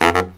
LOHITSAX05-R.wav